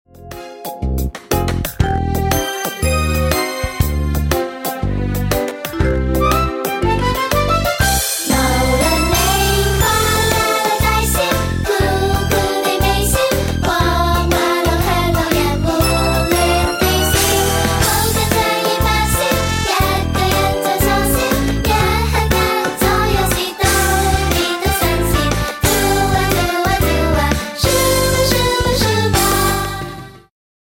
伴奏音樂